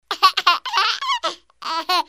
Bebe riendo